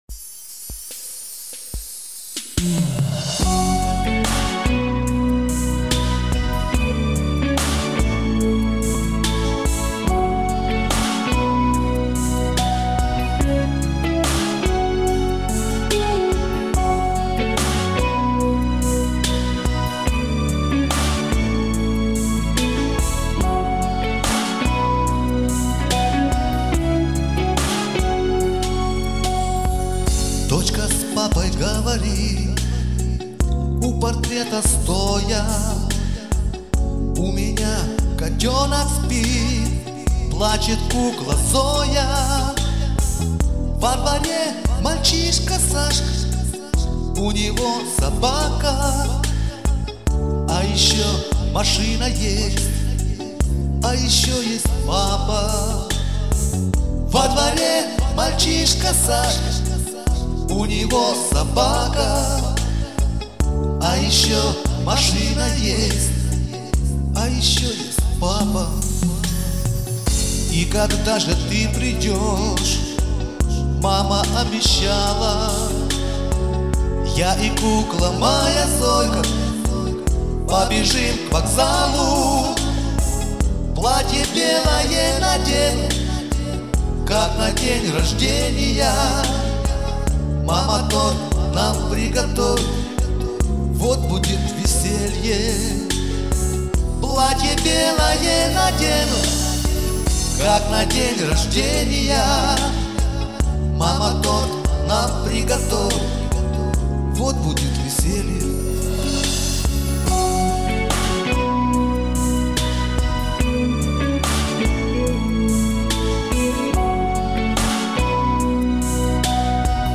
Уличные музыканты - "Афганцы" - Дембельский альбом (Название условное)